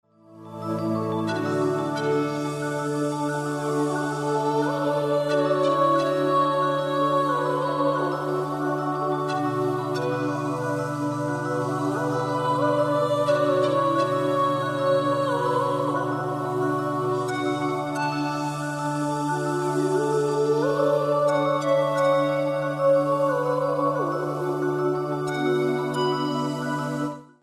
Wszystkie utwory na płycie są ze sobą połączone.